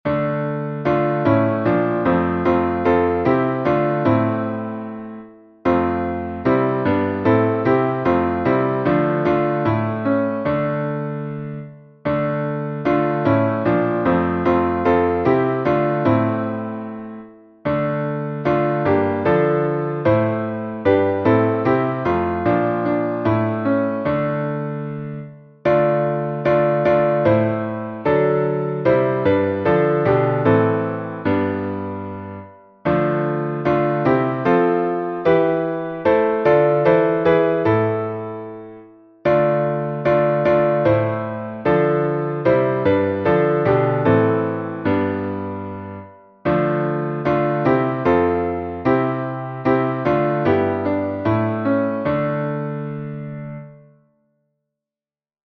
Modo: dórico
salmo_2A_instrumental.mp3